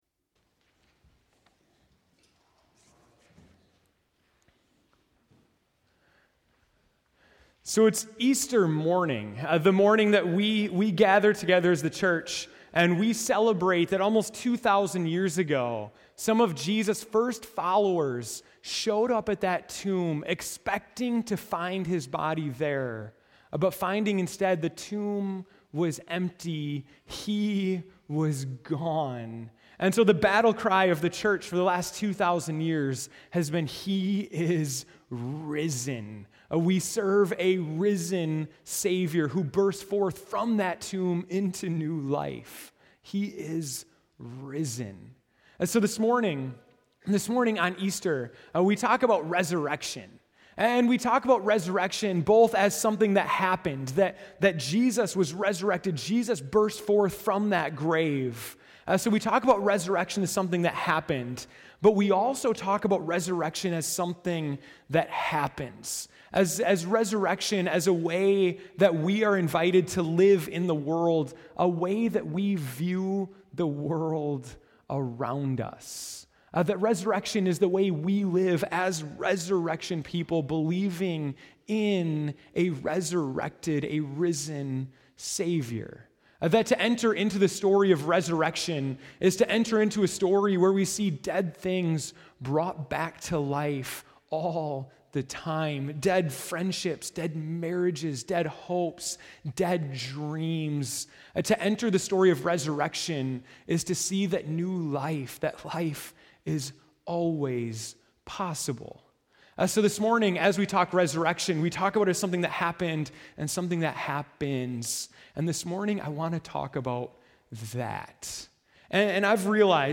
April 5, 2015 (Morning Worship)